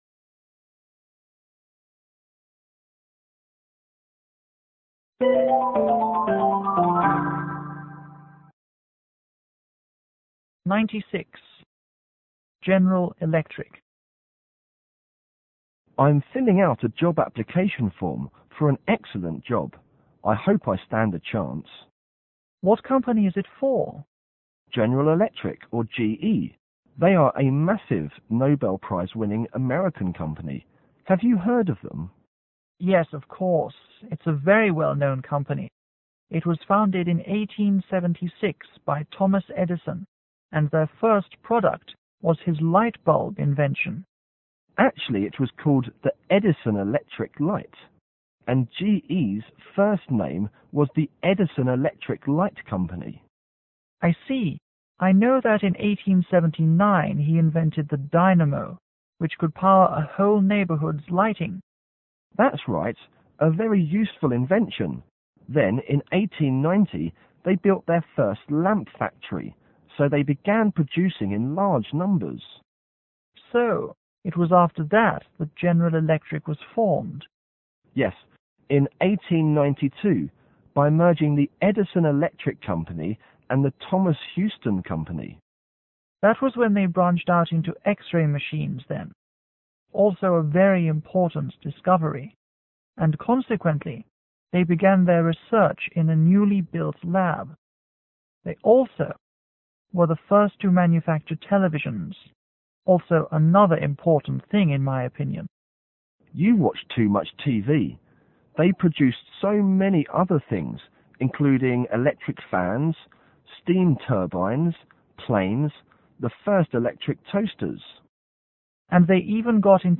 M1 :Man l         M2 :Man 2
M1：男人1       M2：男人2